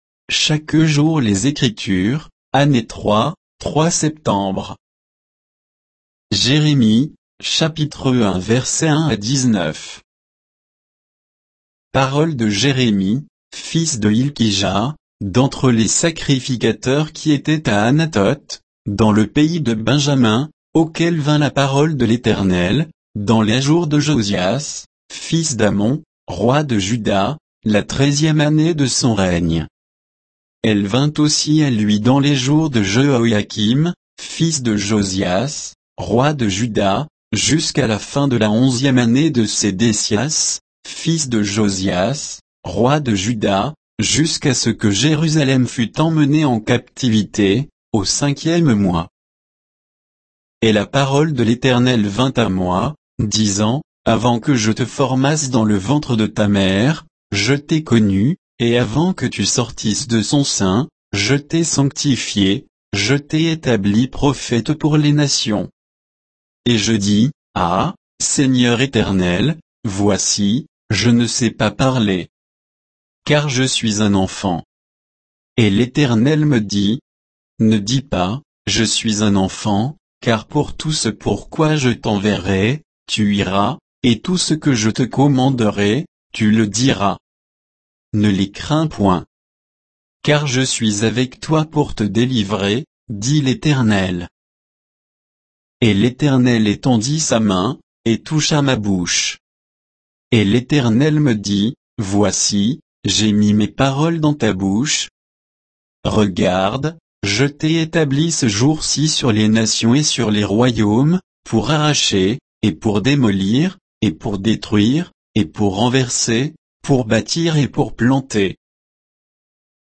Méditation quoditienne de Chaque jour les Écritures sur Jérémie 1, 1 à 19